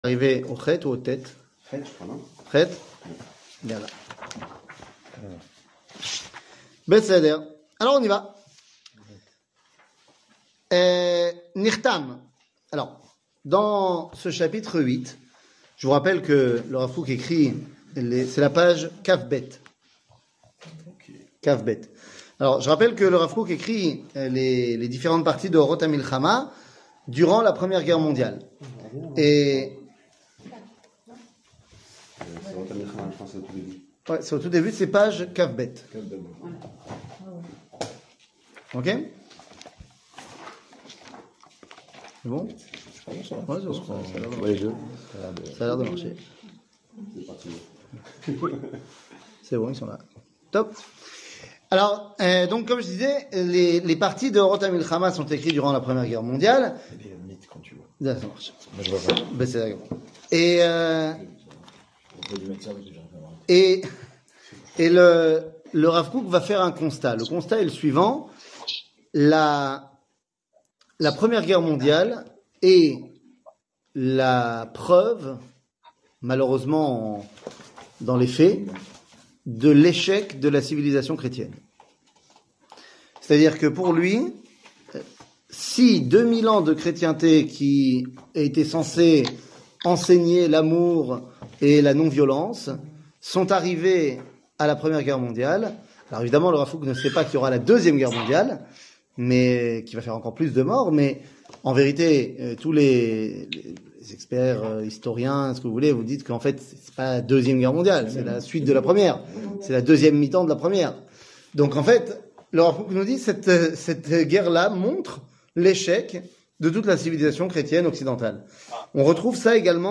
קטגוריה Orot Amilhama 00:43:09 Orot Amilhama שיעור מ 15 ינואר 2024 43MIN הורדה בקובץ אודיו MP3